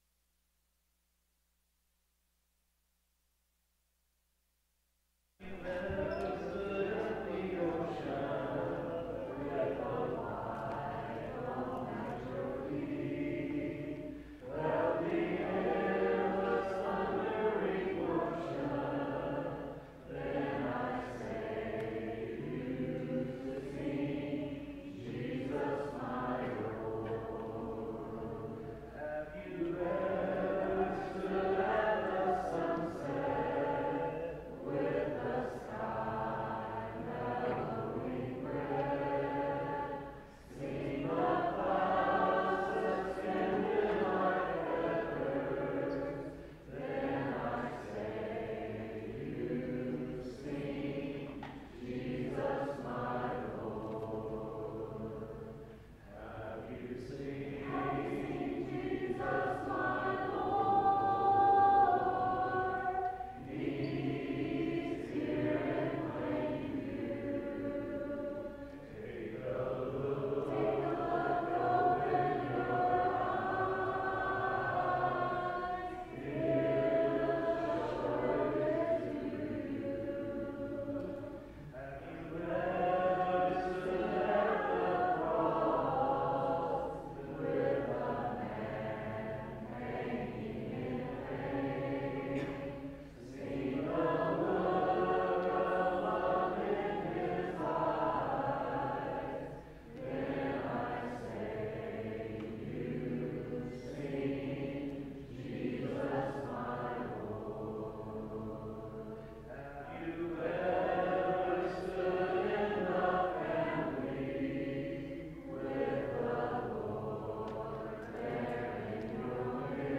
Psalm 78:54, English Standard Version Series: Sunday PM Service